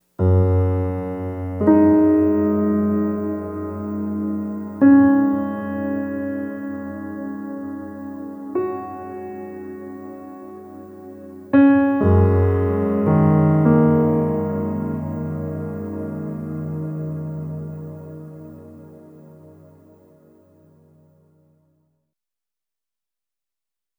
Reverb Piano 02.wav